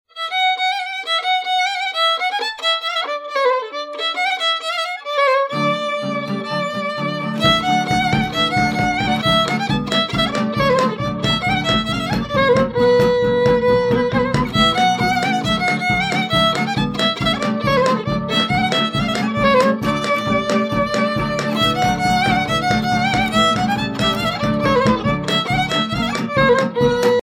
danse : hora (Roumanie)
Pièce musicale éditée